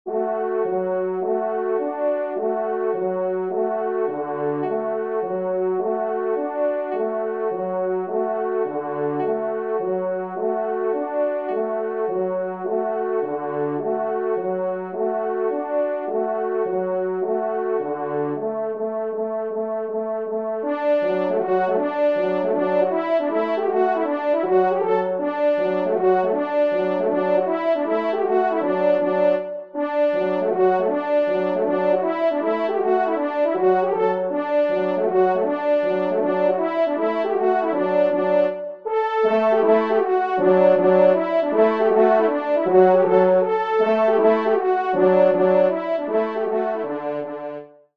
Danses polonaises
4e Trompe